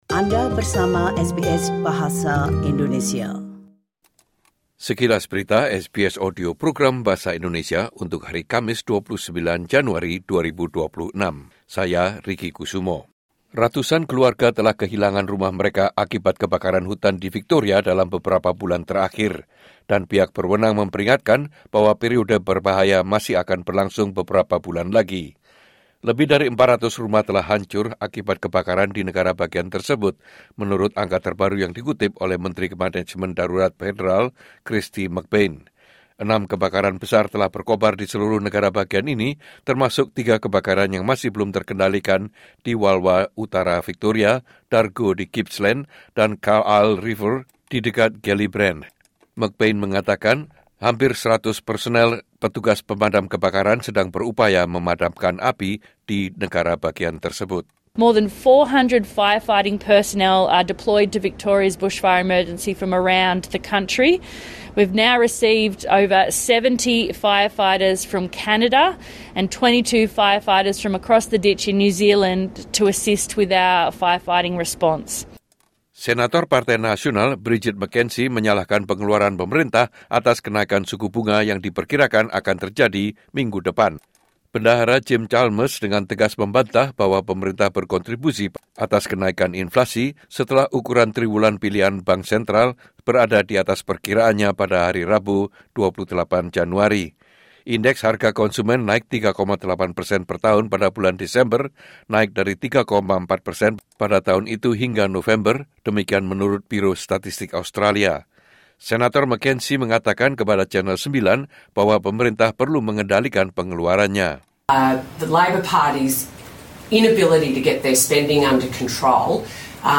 Berita Terkini SBS Audio Program Bahasa Indonesia - Kamis 29 Januari 2026